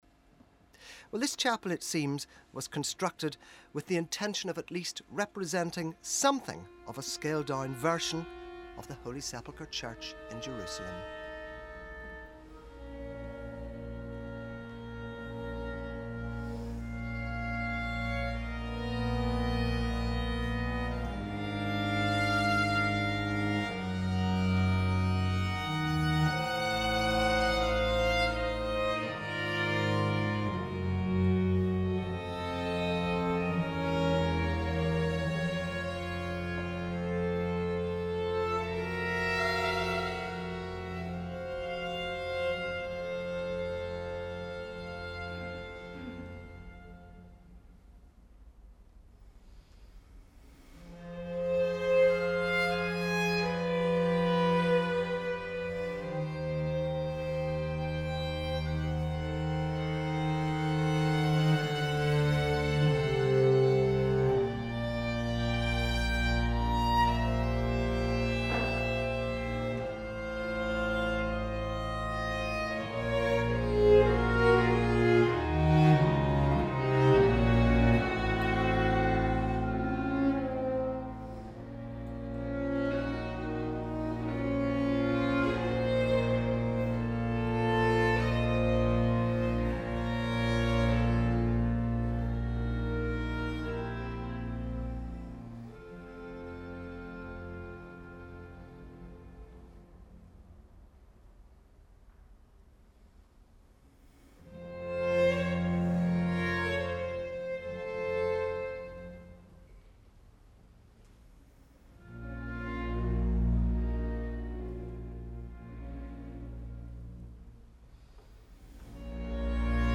Venue: St. Brendan’s Church
Instrumentation Category:Small Mixed Ensemble
Instrumentation Other: 2vn,va,vc, db, lute, hpd
baroque ensemble
Vivaldi wrote two pieces entitled ‘al Santo Sepolcro,’ (at the Holy Sepulchre); this sinfonia for four-part strings, and a sonata.
As suggested by the title, it is a sacred work for Holy Week, most likely written to be performed during a mass at the Pietà. The solemnity of the event is evoked by the tempo of the music, the anguish by the intense, chromatic harmonies of the first movement whilst the descending chromatic steps in the theme of the second movement portrays the pathos of the occasion, asking us to reflect upon the suffering and death of Christ.